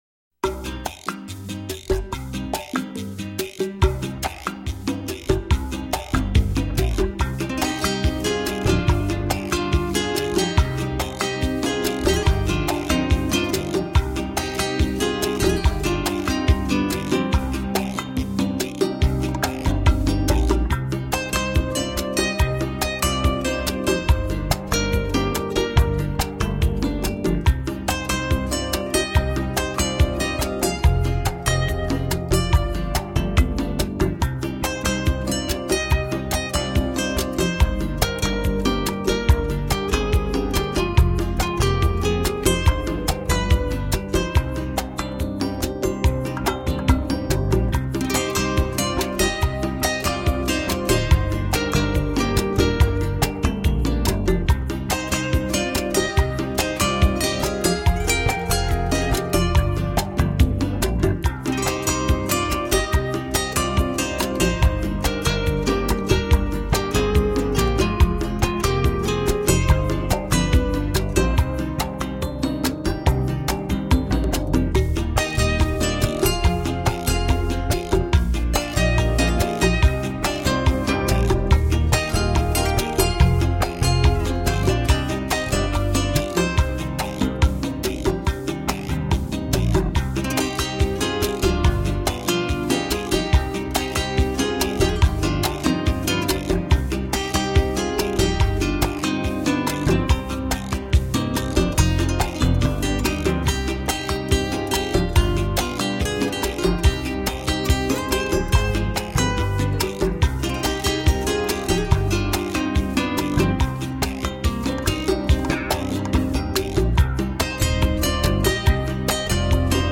Позитивная музыка.